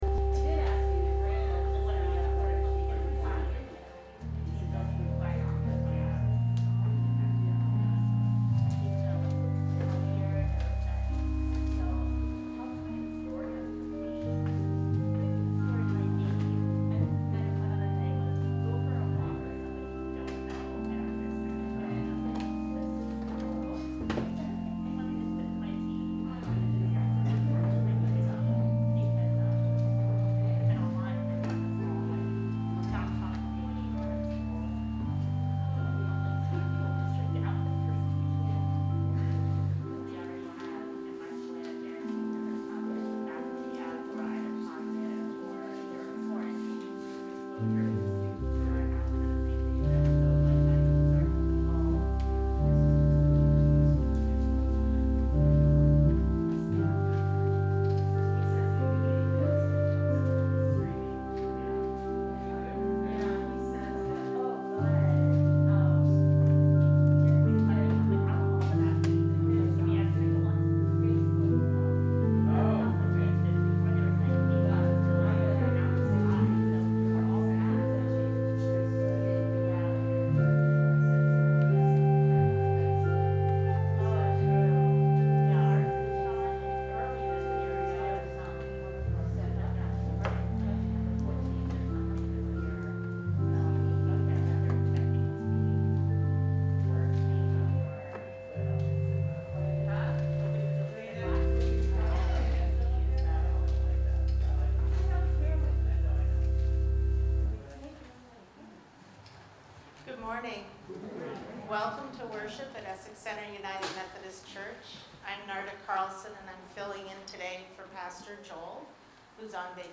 We welcome you to either virtual or in-person worship on Sunday, August 29, 2021 at 10am!
Sermons